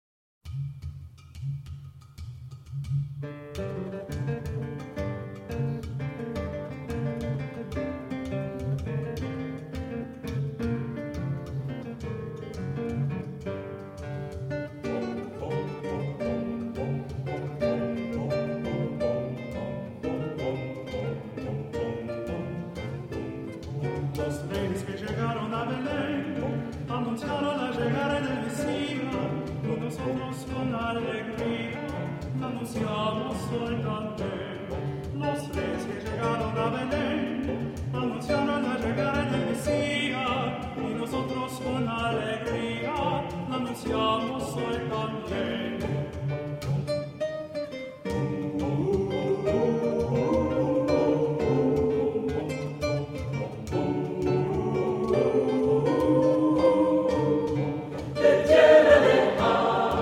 Native American flute
Guitar